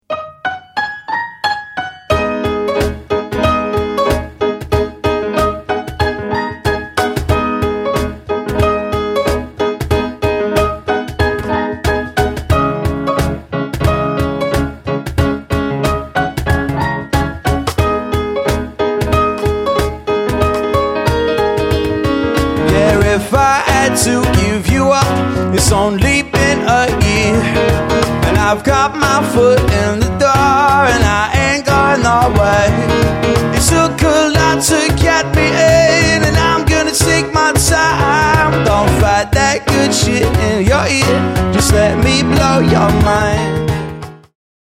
Upbeat acoustic duo for hire in the North
• Stylish pop-funk energy